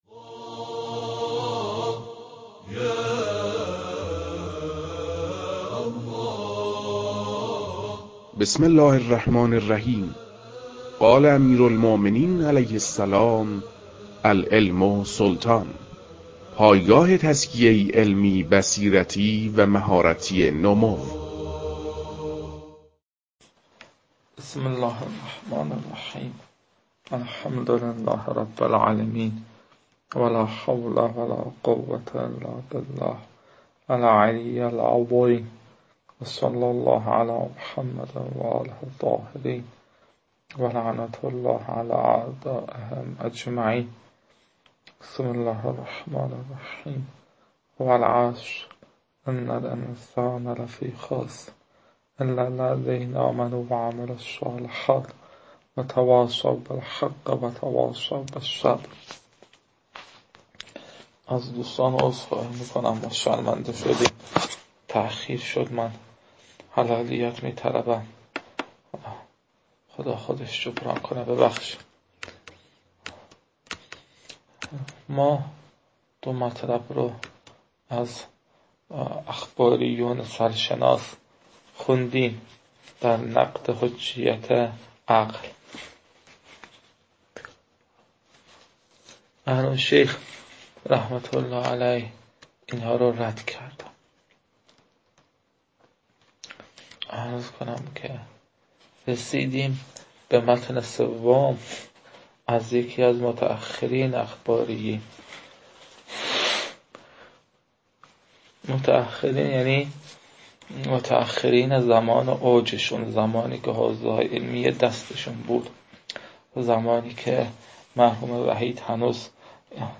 تدریس مبحث رسالة في القطع از كتاب فرائد الاصول متعلق به شیخ اعظم انصاری رحمه الله